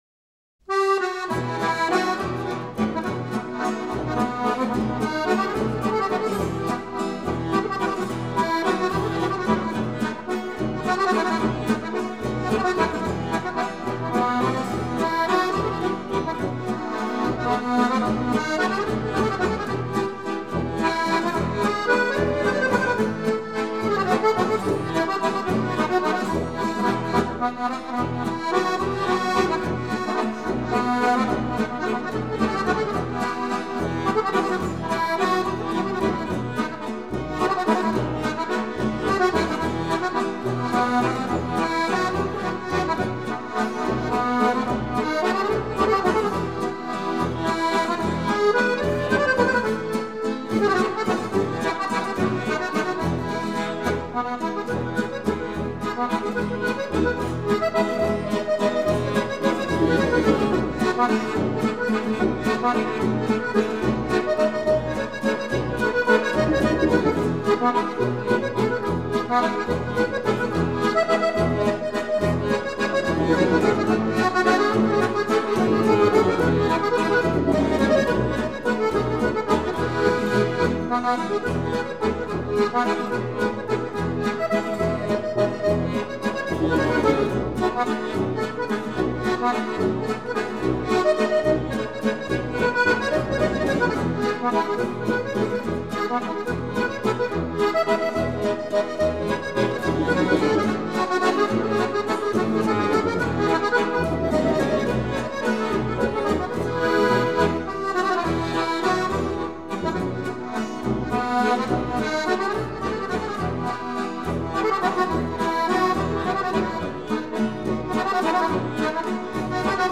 Ticino. Trad., arr. Trio Malcantonese